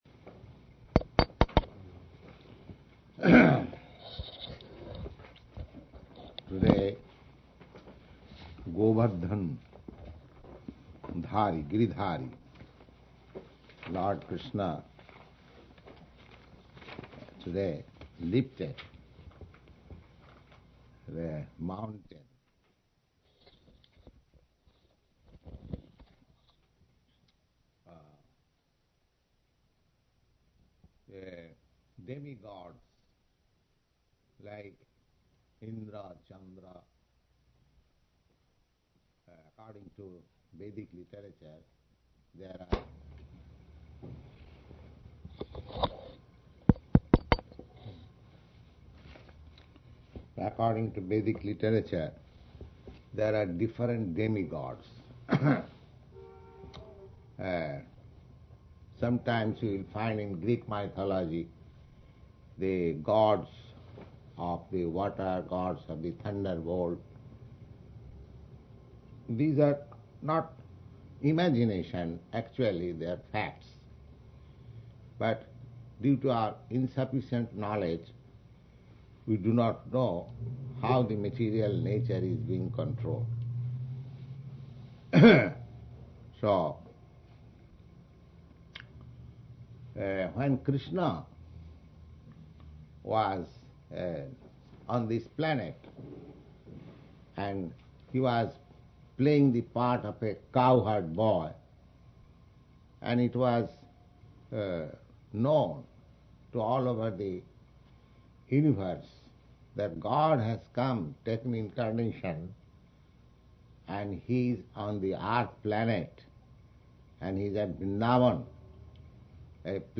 Srila Prabhupada Lecture on Govardhana Puja, November 14, 1966, New York